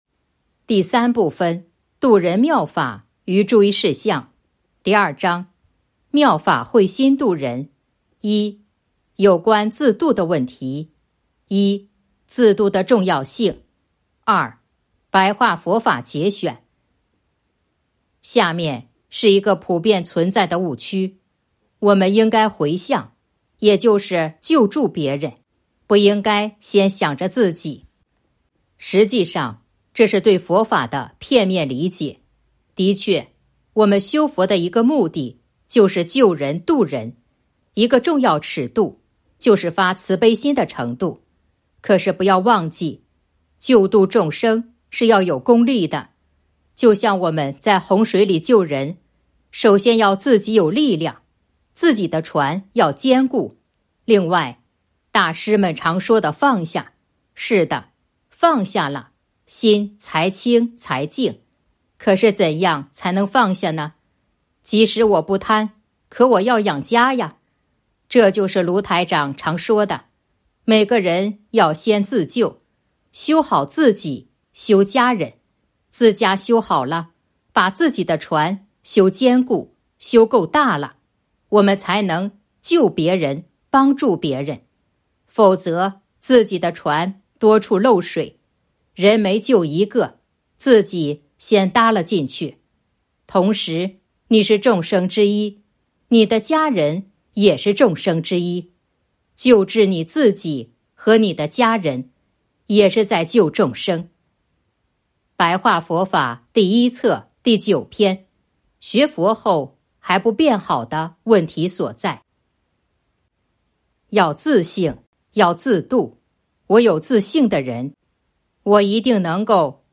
009.（一）自度的重要性 2. 白话佛法节选《弘法度人手册》【有声书】